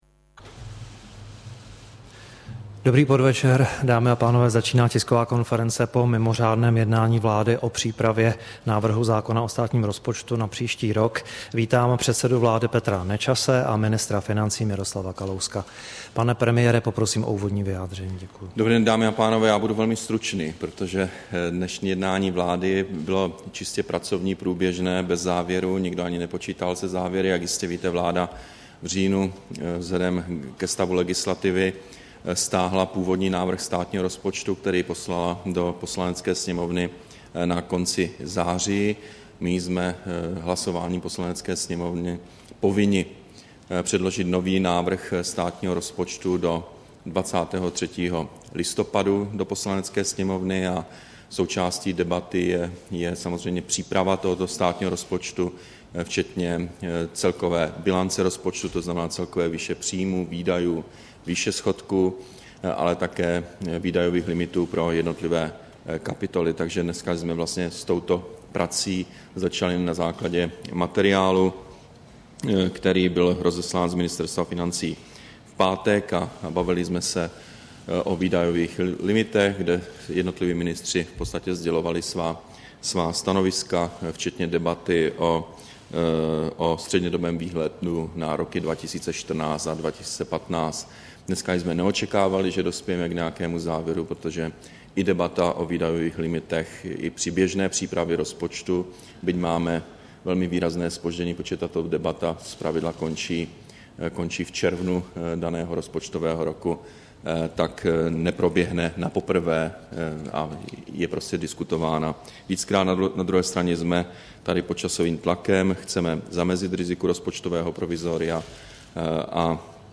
Tisková konference po mimořádném jednání vlády, 5. listopadu 2012